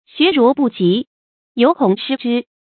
xuá rú bù jí，yóu kǒng shī zhī
学如不及，犹恐失之发音